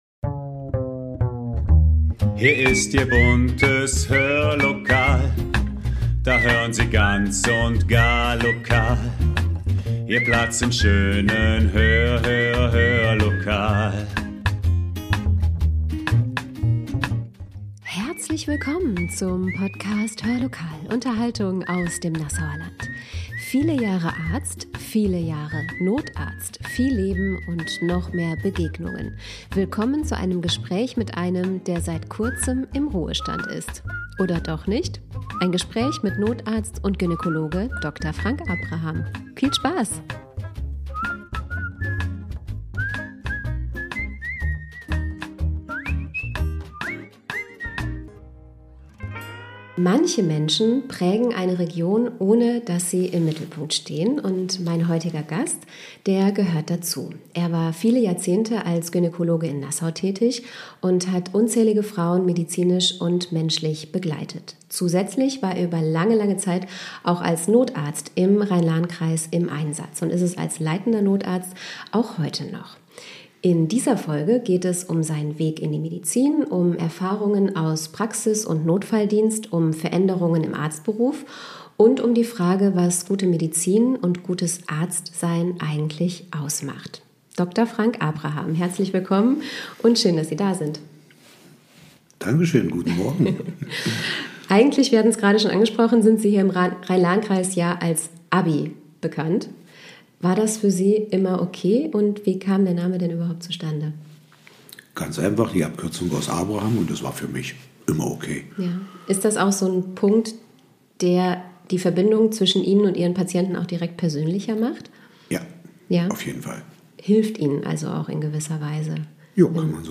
In dieser Folge geht es um seinen Weg in die Medizin, um Erfahrungen aus Praxis und Notfalldienst, um Veränderungen im Arztberuf – und um die Frage, was gute Medizin eigentlich ausmacht. Ein Gespräch